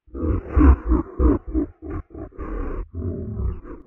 Laugh.ogg